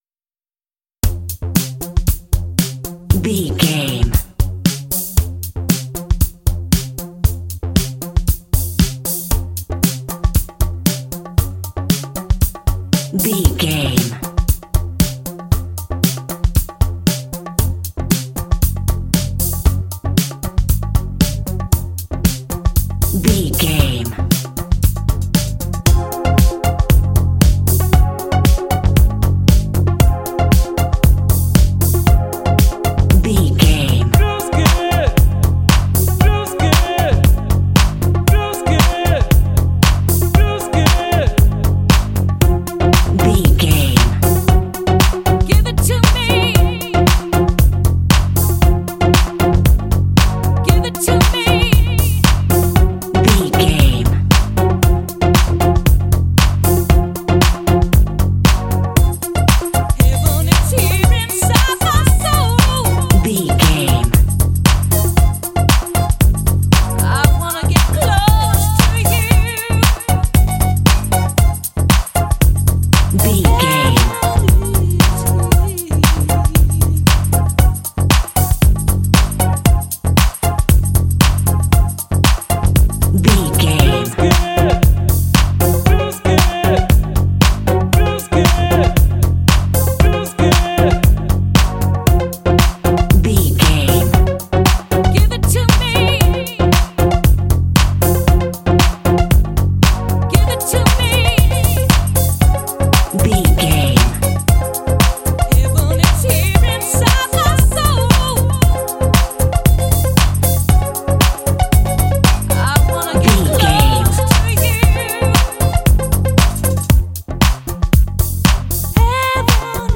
This funky club music track is ideal for adult action games.
Fast paced
Phrygian
bouncy
groovy
synthesiser
conga
bass guitar
drums
vocals
synth pop